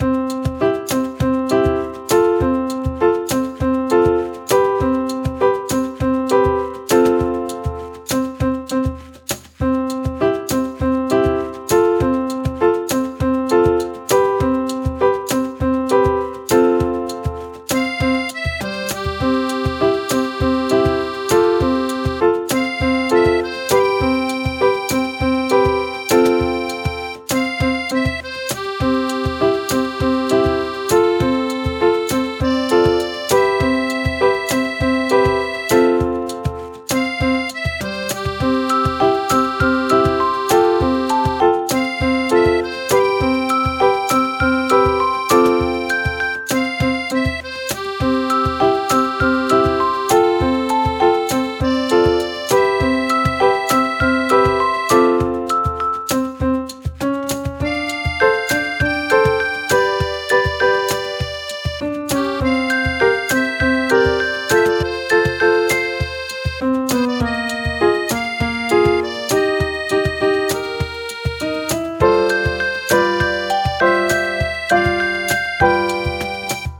明るい楽曲
【イメージ】日常、おだやか など